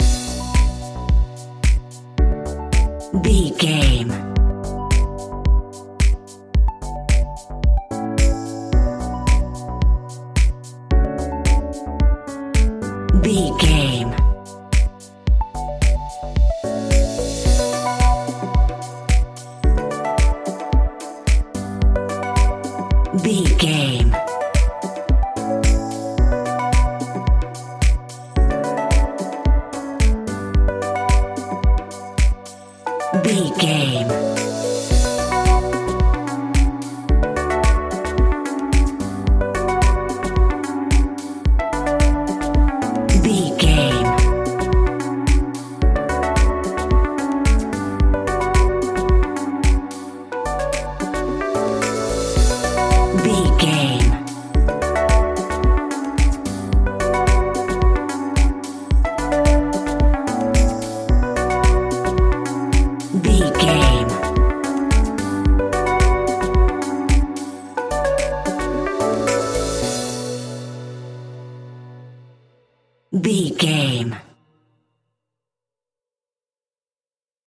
Aeolian/Minor
groovy
dreamy
tranquil
smooth
futuristic
drum machine
synthesiser
house
electro
synth pop
synth leads
synth bass